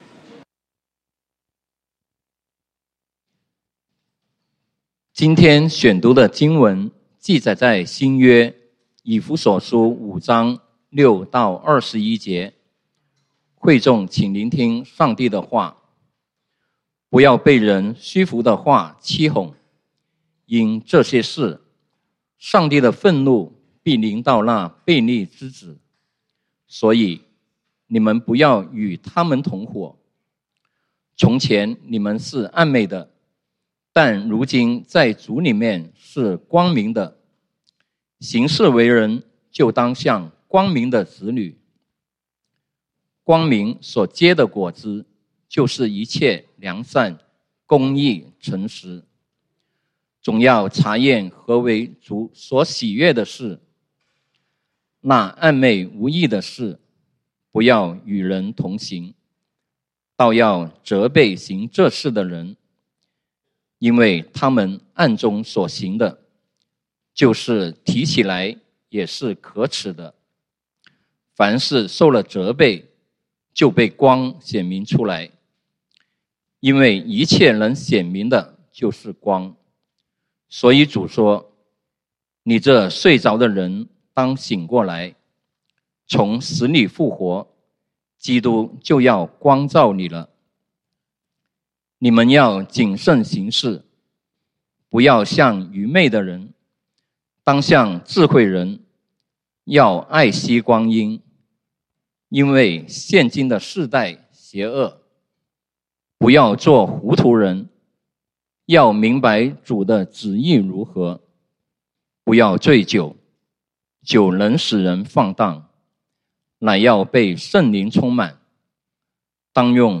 11/26/2023 講道經文：以弗所書Ephesians 5:6-21 本週箴言：以弗所書Ephesians 5:16-17 「要愛惜光陰，因為現今的世代邪惡。